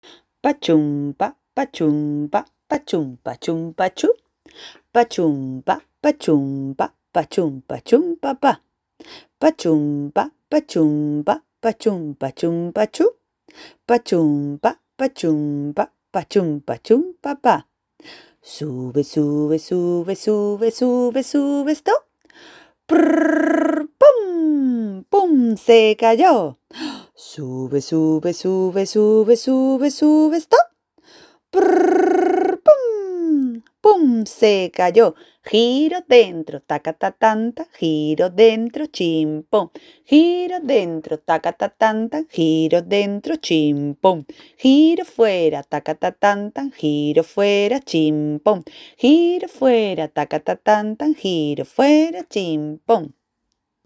Triple.